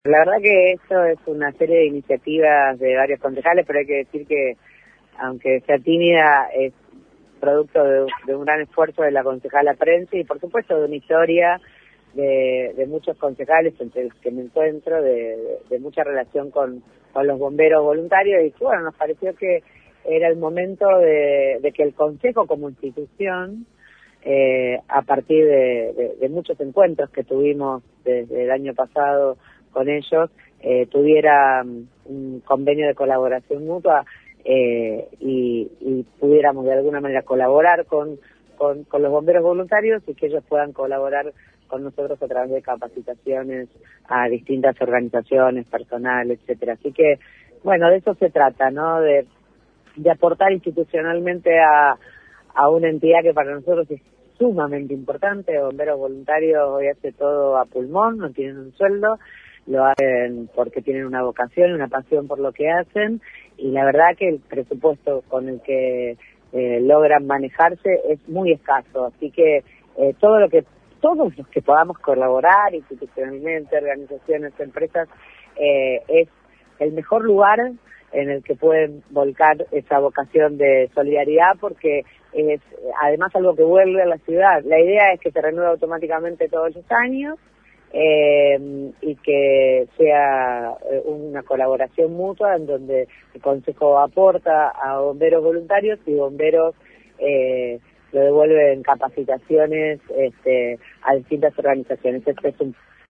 El proyecto fue presentado por la gestión de Sabrina Prence, concejala de La Libertad Avanza y fue aprobado por el cuerpo completo del Concejo Municipal confirmando la ayuda que llegará de forma mensual para los Bomberos Voluntarios. En el acto de la firma, Prence estuvo presente pero no quiso hablar, por eso mismo el móvil de LT3 tomó la palabra de María Eugenia Schmuck, también presente en el acto.
maria-eugenia-shmuk-movil.mp3